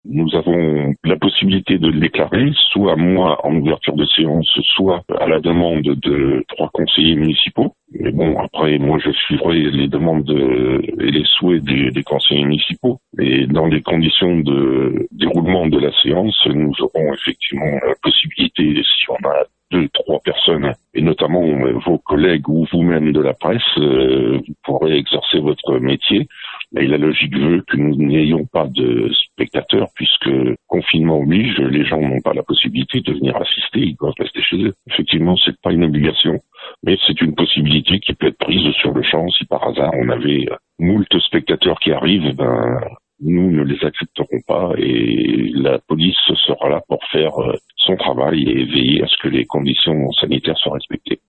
On écoute le maire Eric Authiat :